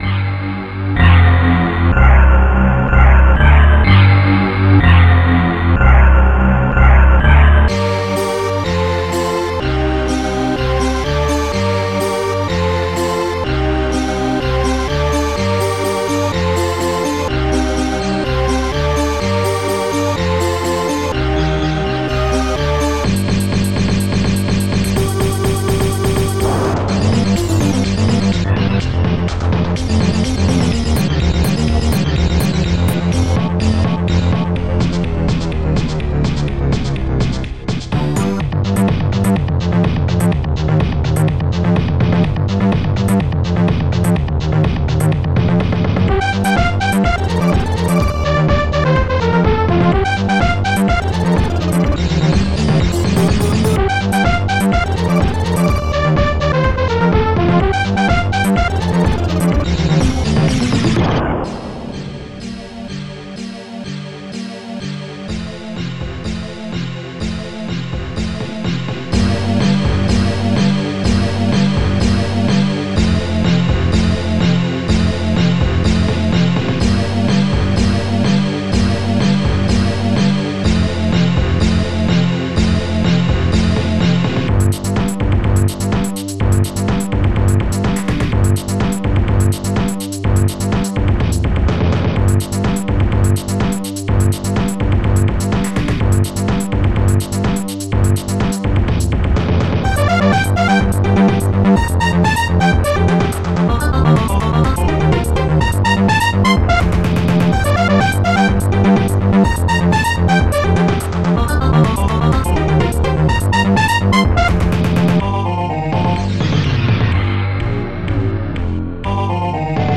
st-03:strings6
st-02:synthebass
st-01:snare1
st-01:bassdrum2
st-01:hihat1